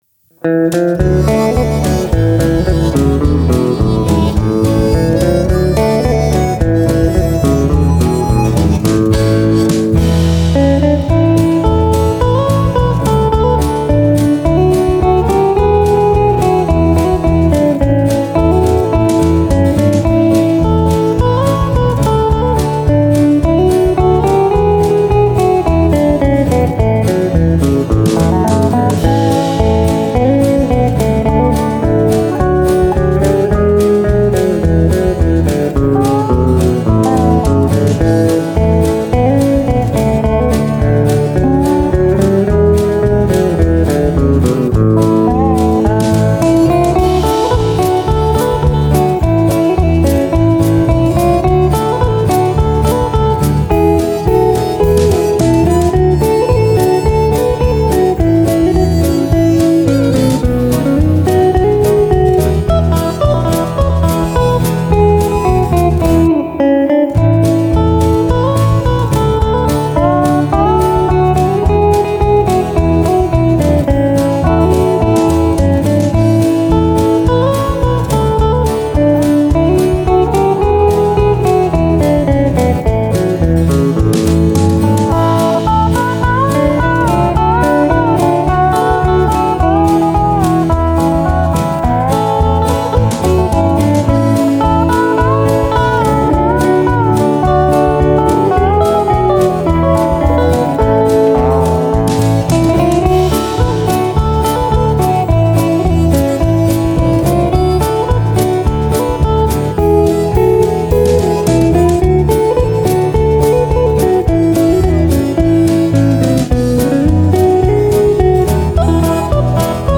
the track blends humour, energy, and masterful musicianship